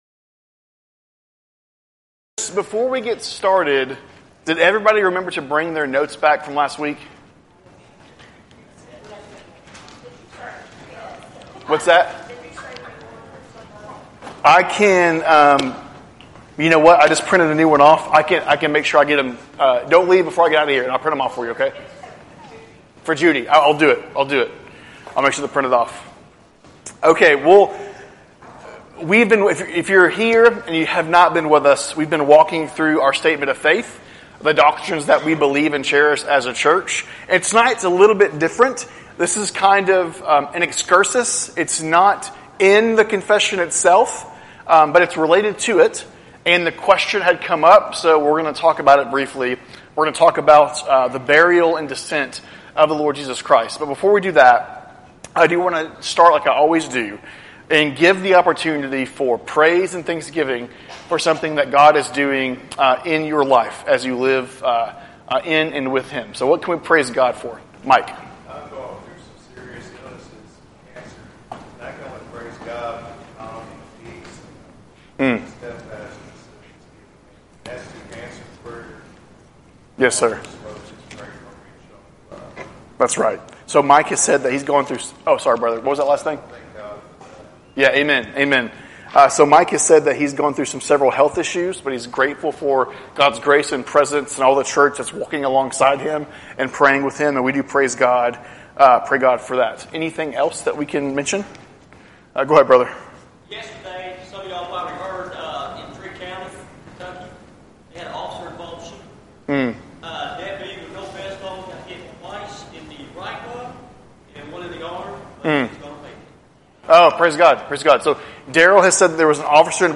Wednesday Evening Bible Studies - 6:30pm